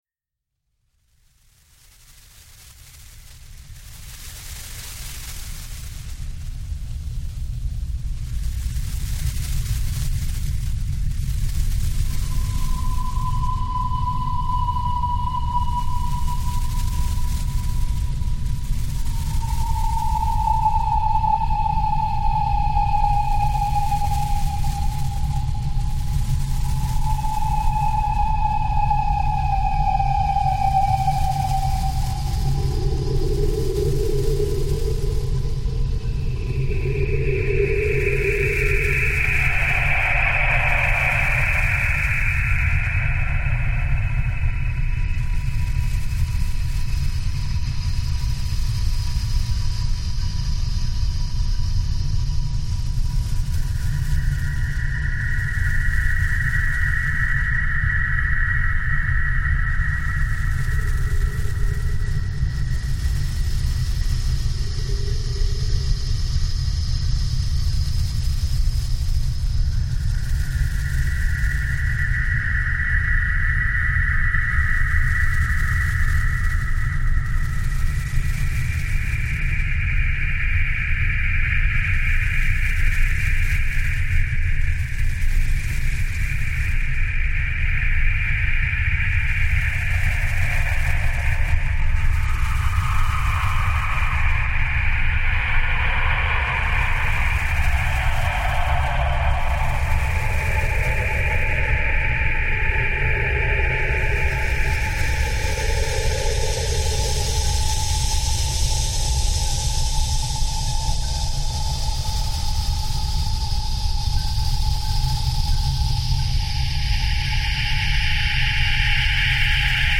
Finnish birdsong reimagined